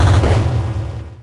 Techmino/media/effect/chiptune/clear_5.ogg at b3ca43fa696bcea94ae6b670adb26efb44fe7c21
clear_5.ogg